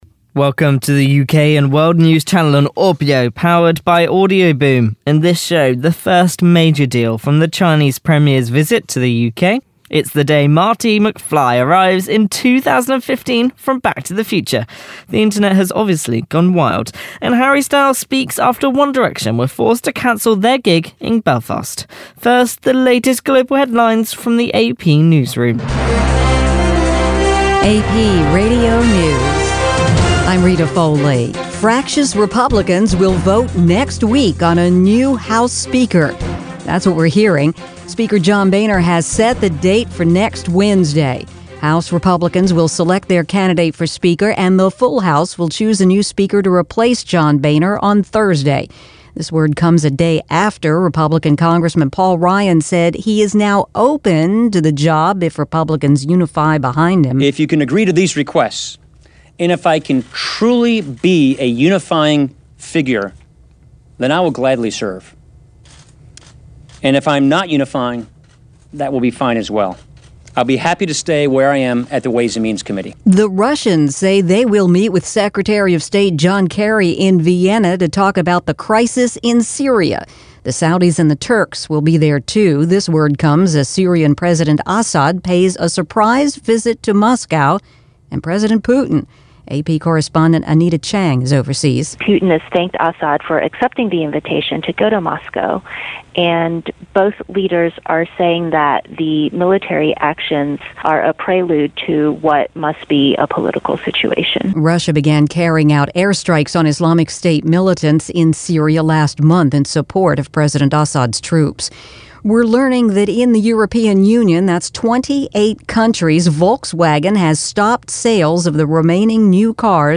The latest UK and World News, powered by audioBoom.